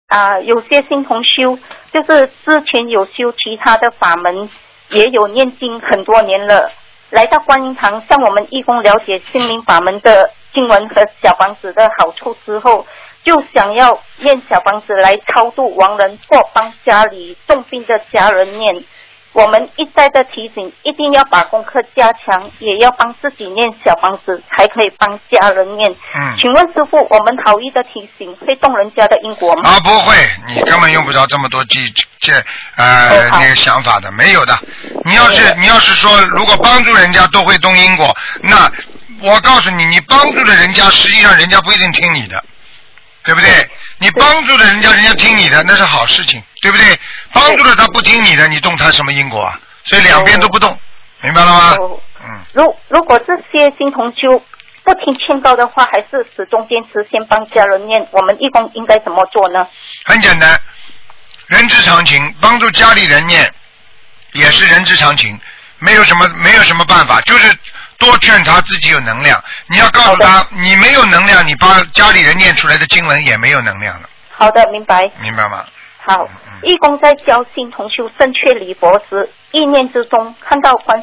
Tanya Jawab
Pendengar wanita: Ada teman se-Dharma yang baru, sebelumnya menekuni pintu Dharma lain, juga sudah melafalkan paritta selama bertahun-tahun, setelah datang ke Guan Yin Tang memahami paritta Xin Ling Fa Men dan manfaat dari Xiao Fang Zi dari relawan kita, kemudian hanya ingin melafalkan Xiao Fang Zi untuk menyeberangkan almarhum, atau membantu keluarga yang sakit keras melafalkan.